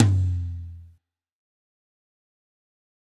Tom_F3.wav